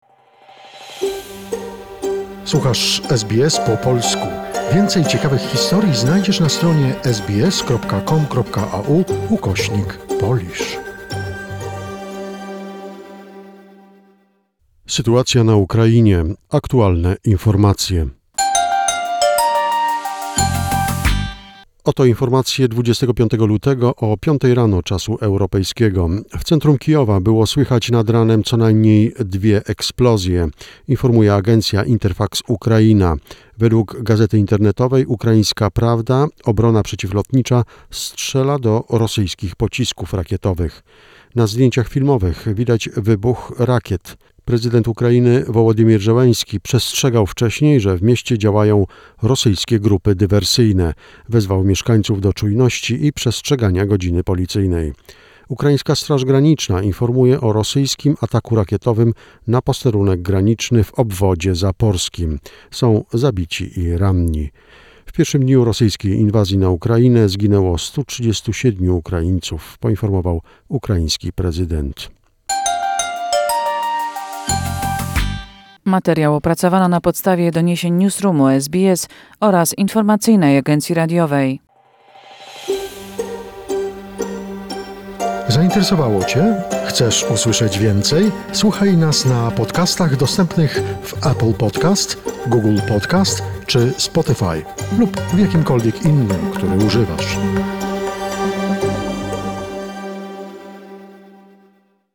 The most recent information and events regarding the situation in Ukraine, a short report prepared by SBS Polish.